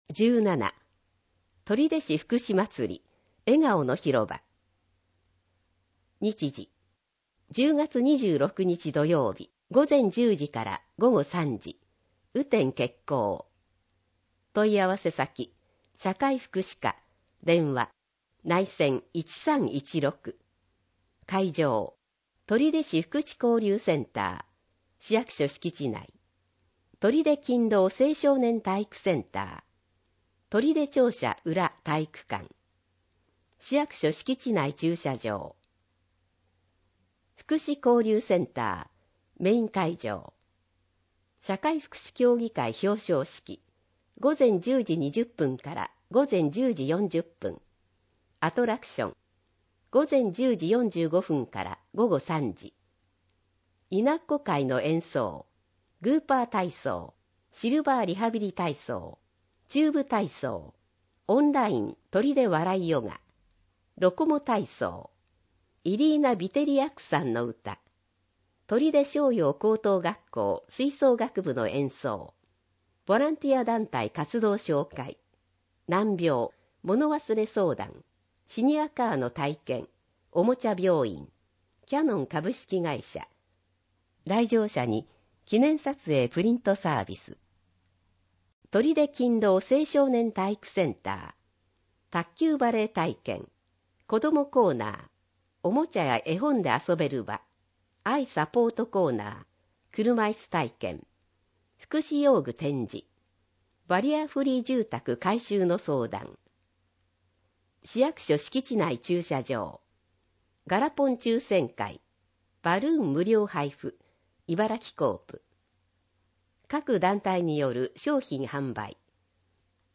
取手市の市報「広報とりで」2024年10月1日号の内容を音声で聞くことができます。音声データは市内のボランティア団体、取手朗読奉仕会「ぶんぶん」の皆さんのご協力により作成しています。